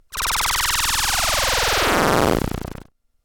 不思議系効果音です。
ピュルピュル・・・